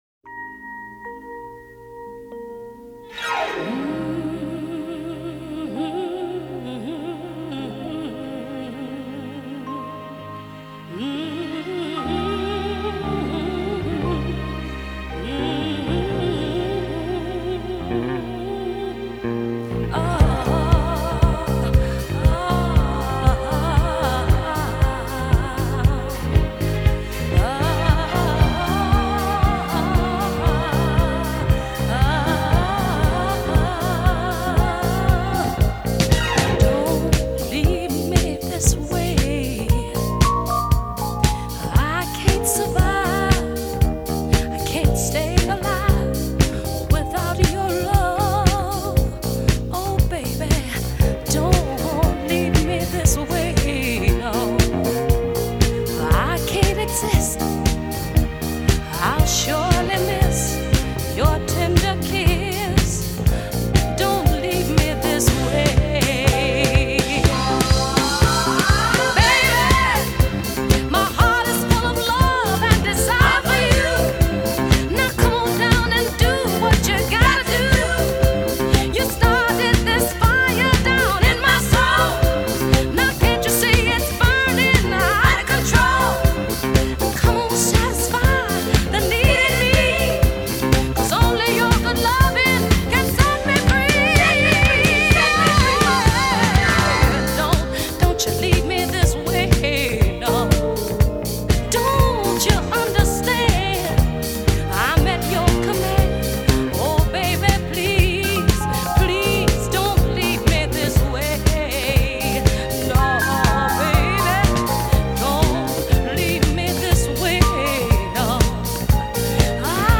disco classic